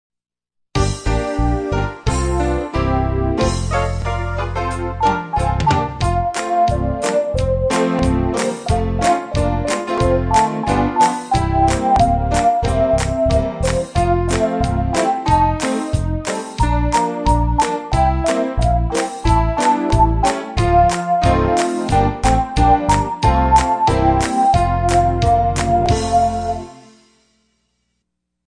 Description: This is the new Adventurer Song after the 2006 change, played without words.